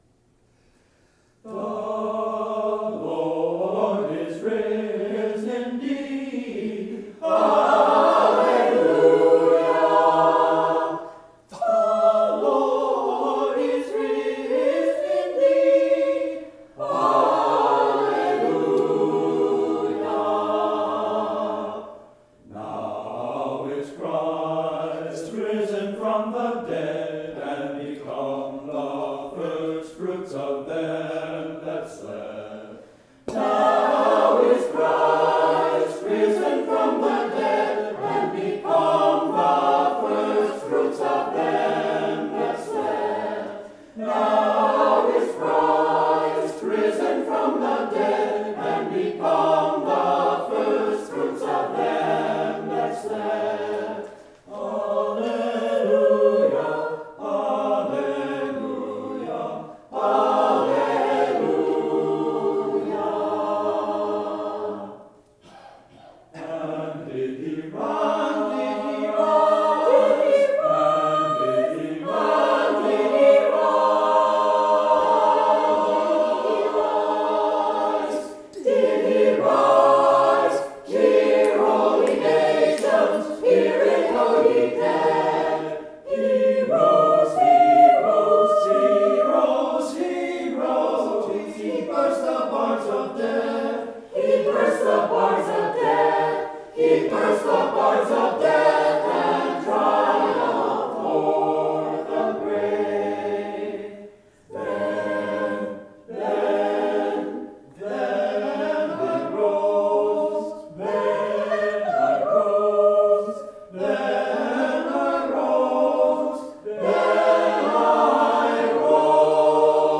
Choir Anthems Easter 2015